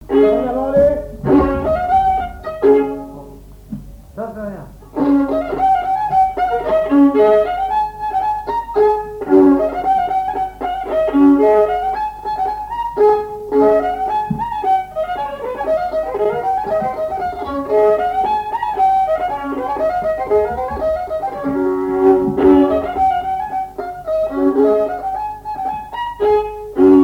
Vendée
danse : branle : avant-deux
Pièce musicale inédite